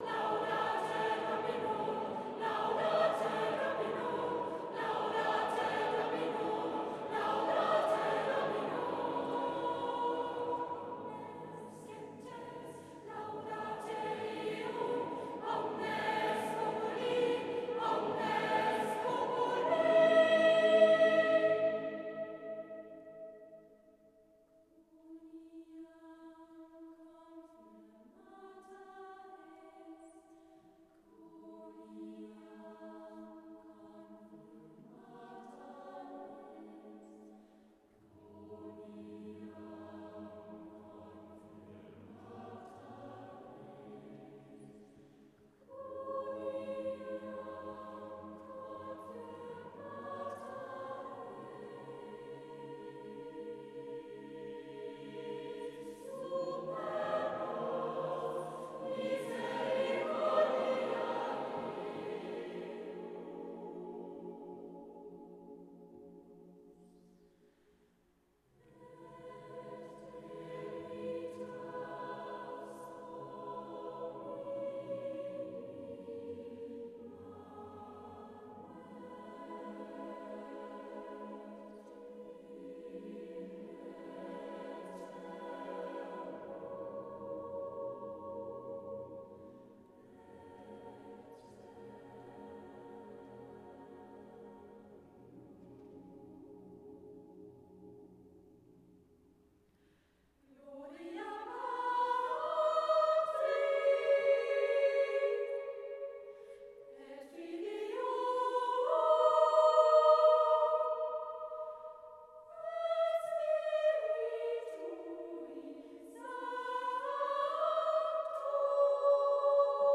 Konzertmitschnitte aus den Jahren 2005 bis 2008